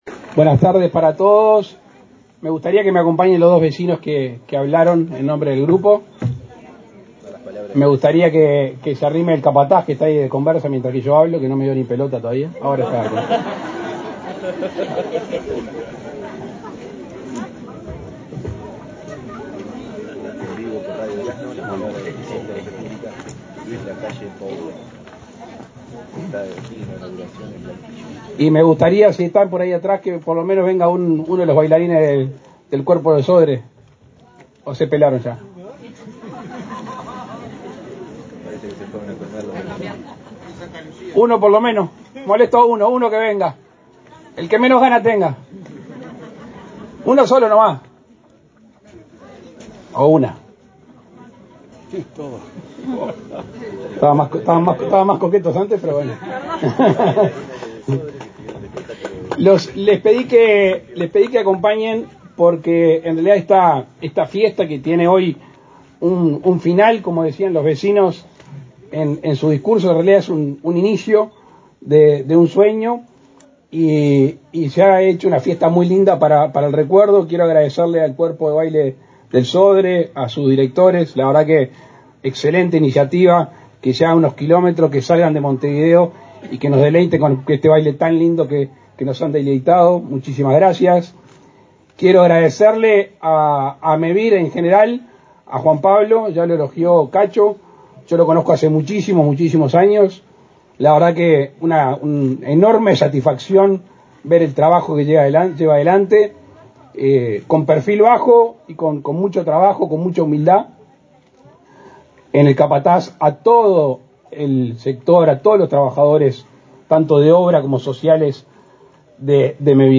Palabras del presidente de la República, Luis Lacalle Pou
El presidente de la República, Luis Lacalle Pou, participó en la inauguración de 39 soluciones habitacionales de Mevir en Durazno, este 19 de mayo.